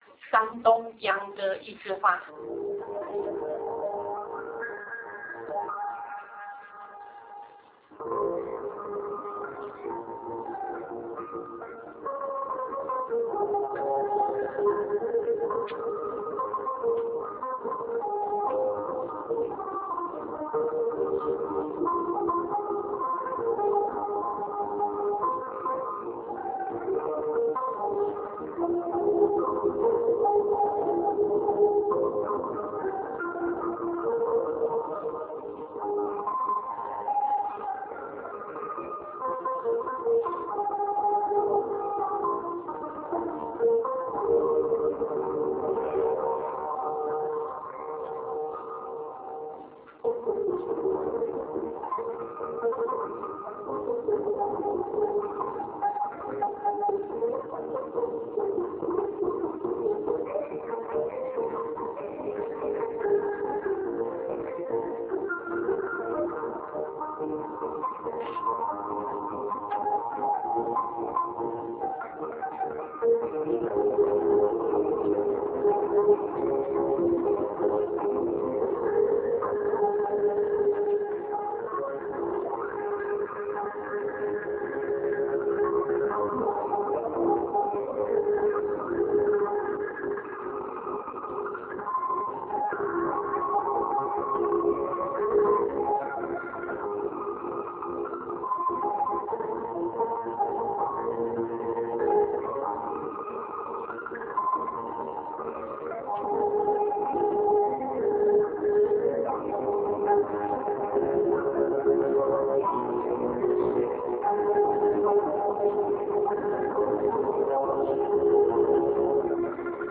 ShanDongYangGe_YiZhiHua_56k.ra